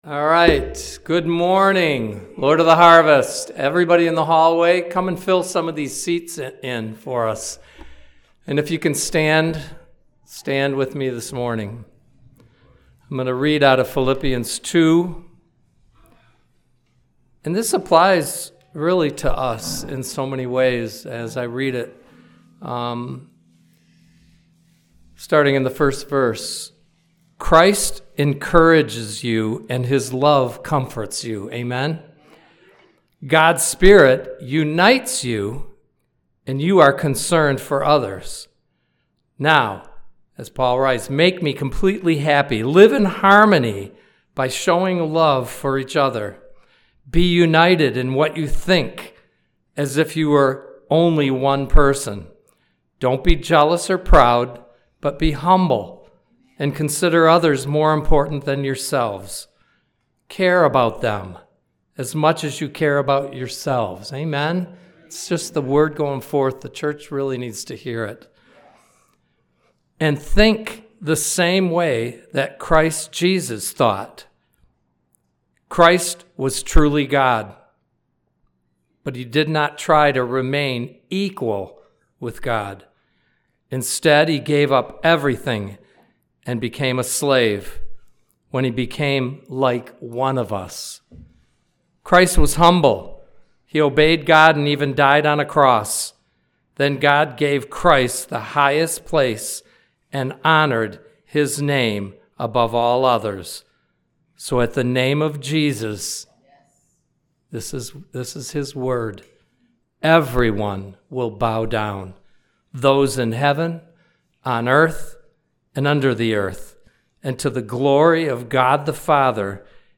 Series: Prophetic Nature of the Church Service Type: Sunday Service